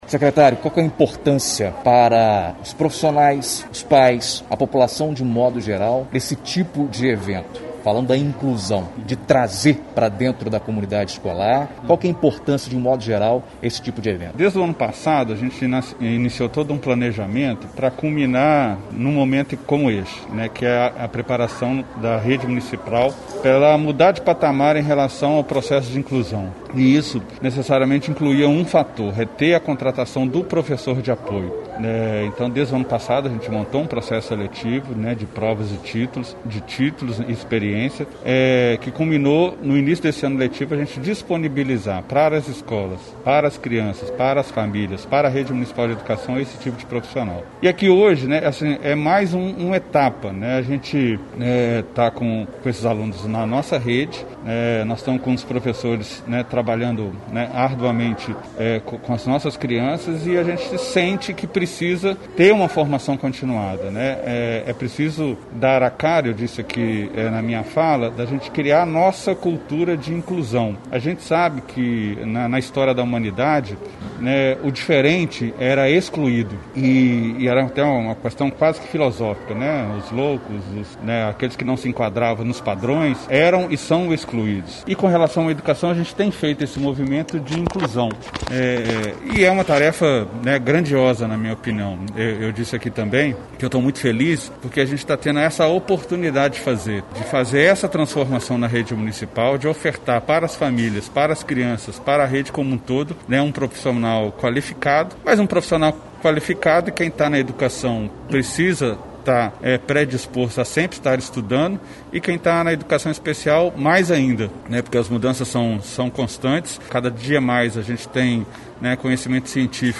entrevista o secretário municipal de educação